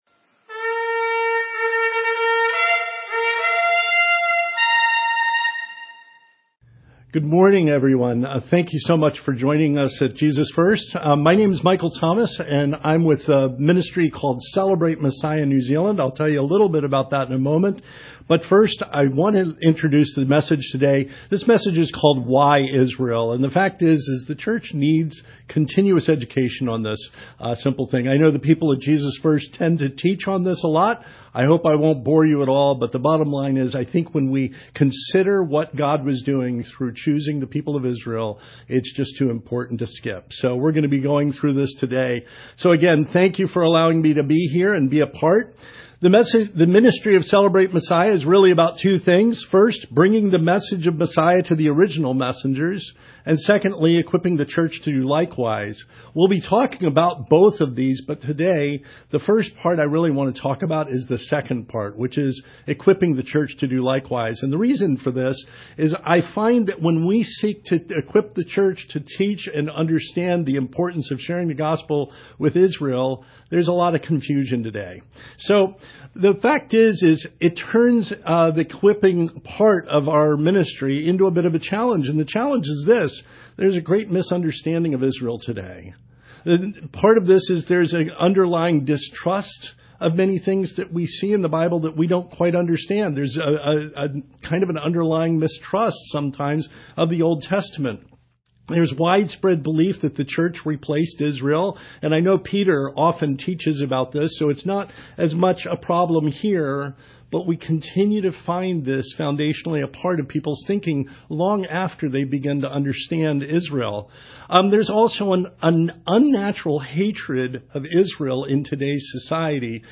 We will see clearly from cover to cover of our Bibles that God pursues all His people with determination & passion to living in a loving, restored relationship with Him. Online Sunday Morning Service 11th May, 2025 Slides from this service are below.